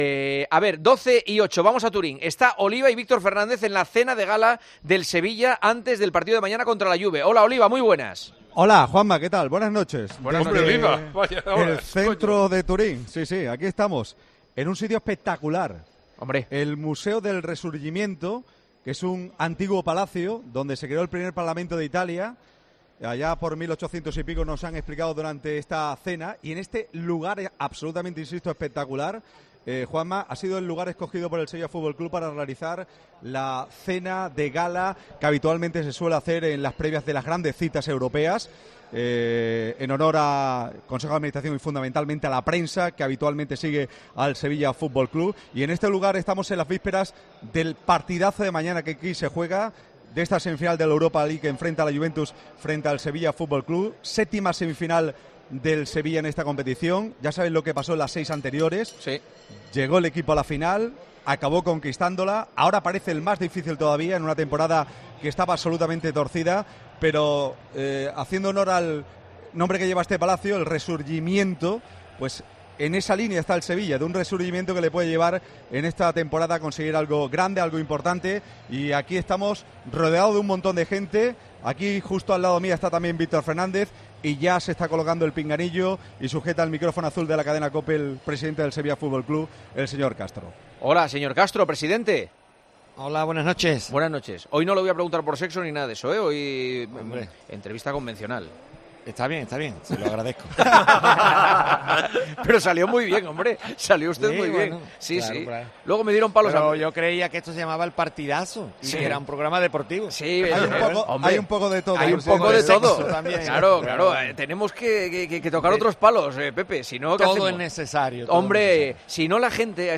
AUDIO: Juanma Castaño entrevista al presidente del Sevilla, Pepe Castro, en la previa del partido de ida de semifinales de la Europa League ante la...